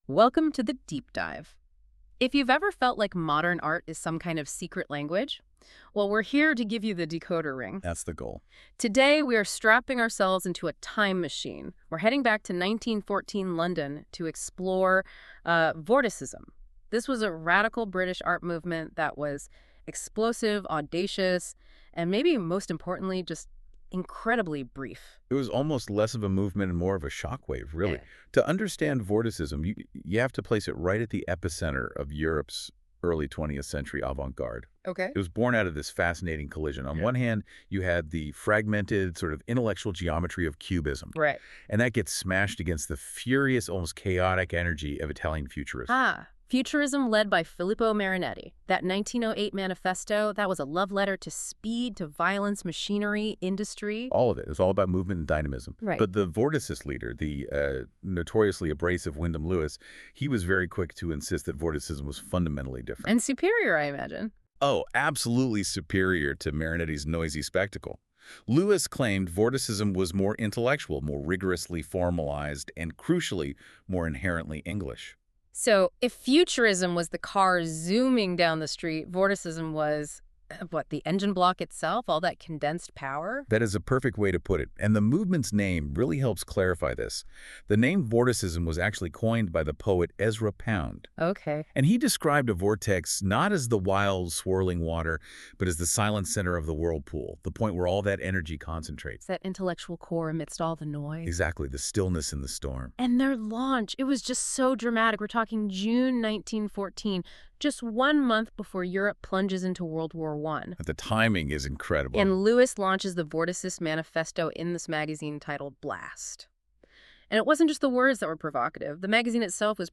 A discussion on Vorticism 1913-1915 (created by NotebookLM from my notes):
44-01-Vorticism-Google-NLM-discussion.mp3